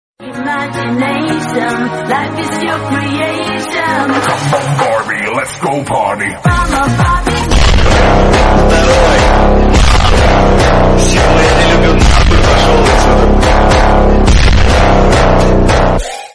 PHONK